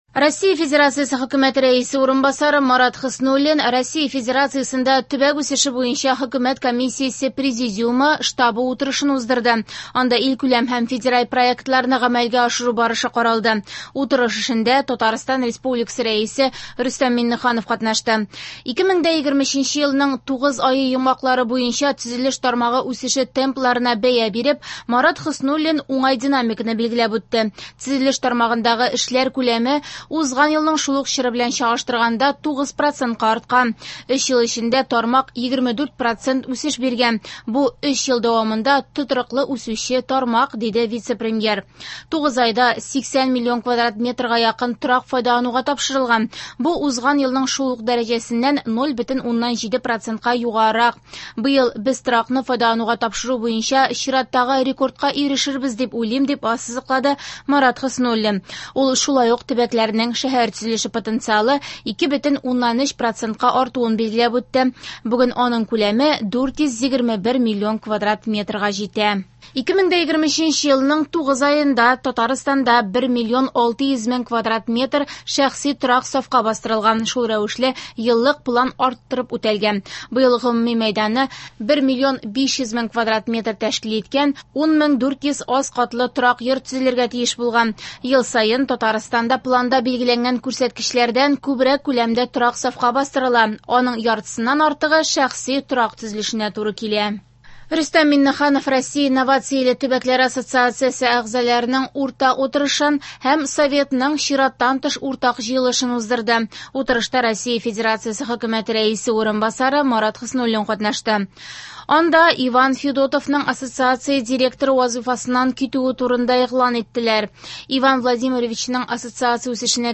Яңалыклар (13.10.23)